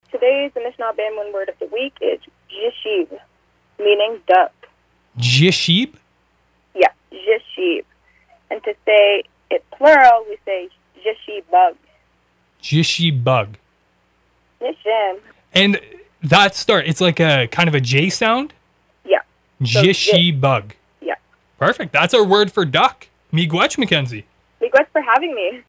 ANISHINAABEMOWIN WORD OF THE WEEK: